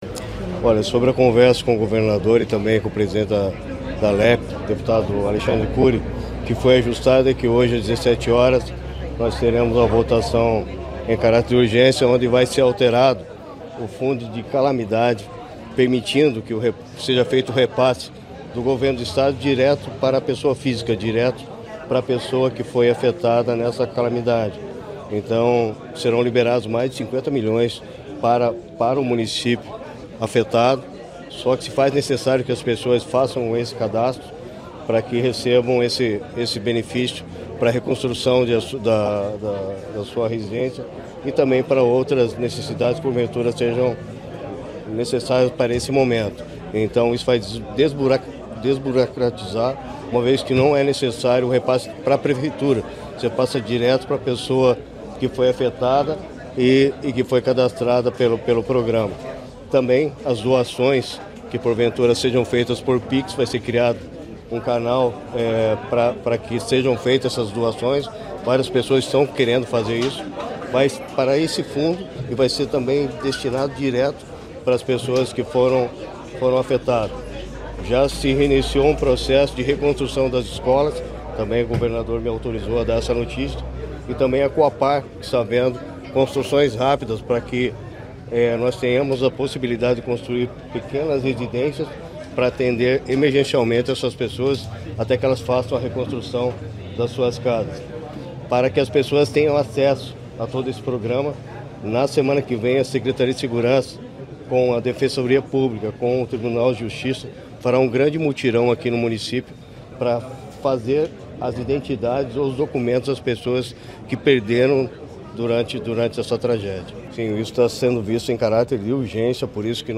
Sonora do secretário da Segurança Pública, Hudson Teixeira, sobre o projeto de lei que altera as regras de repasse do Fecap
COLETIVA - HUDSON TEIXEIRA.mp3